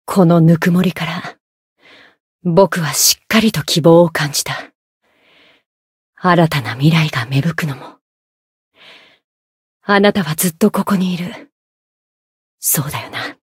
灵魂潮汐-迦瓦娜-春节（摸头语音）.ogg